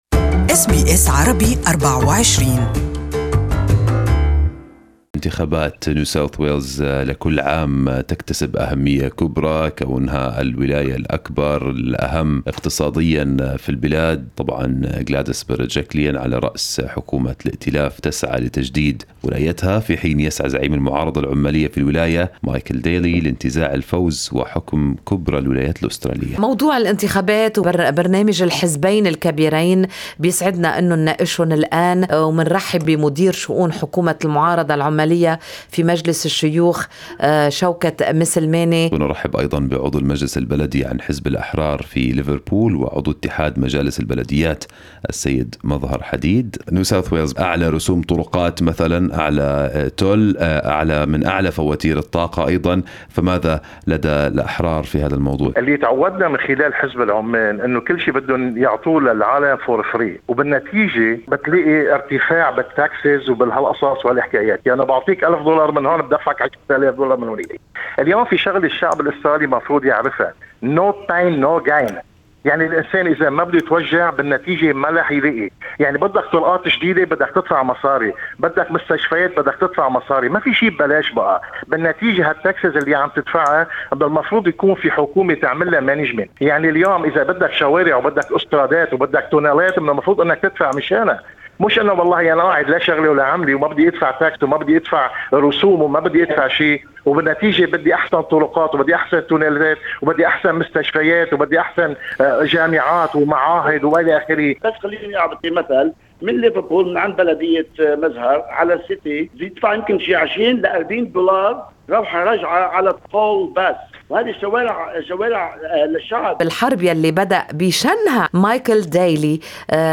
SBS Arabic24 held a panel between the whip of the opposition government at NSW parliament, Mr Shawkat Maslamani and Liberal member of Liverpool Council, Mr Mazhar Hadeed to discuss the main topics to be addressed in the upcoming NSW election on the 23rd of March 2019.